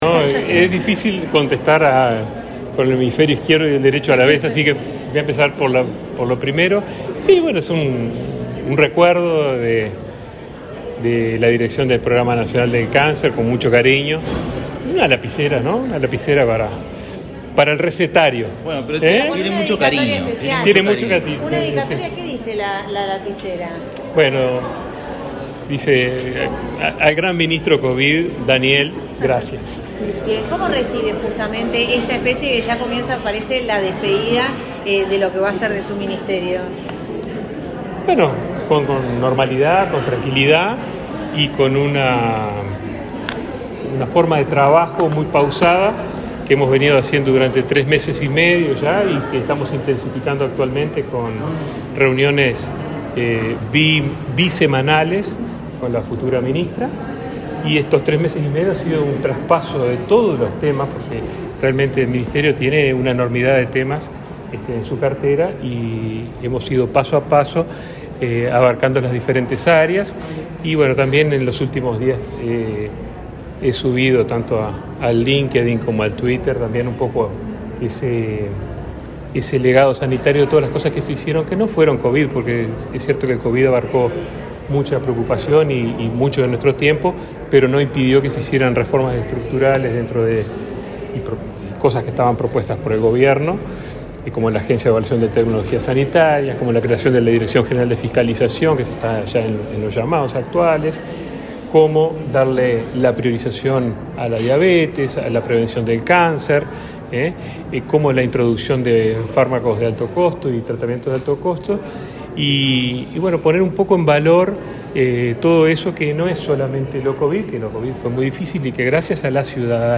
El ministro de Salud Pública, Daniel Salinas, dialogó con la prensa luego de participar en el acto realizado en esa cartera con motivo del Día Mundial